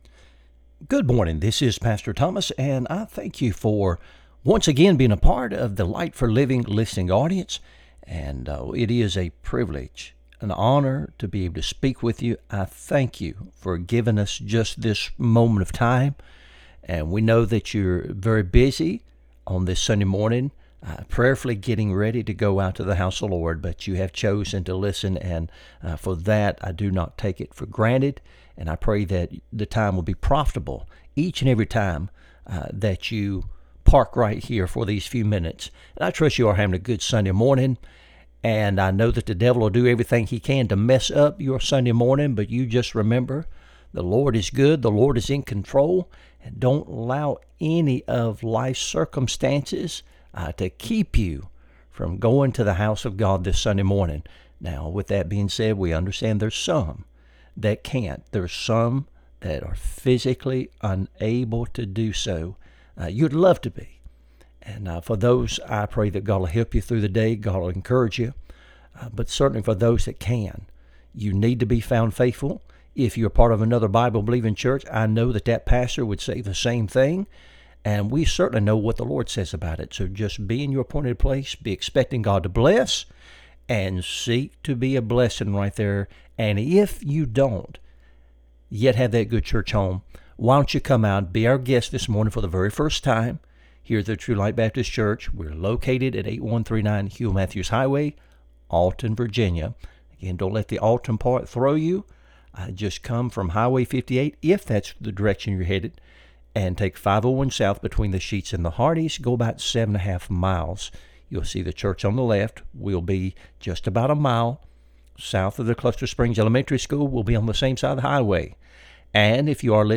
Light for Living Radio Broadcast